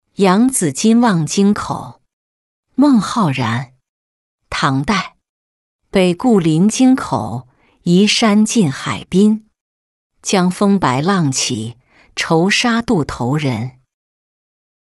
扬子津望京口-音频朗读